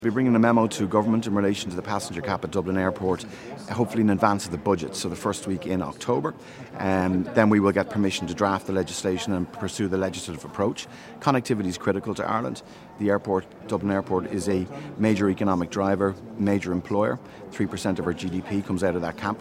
Minister Darragh O' Brien, says Dublin airport is a major economic driver: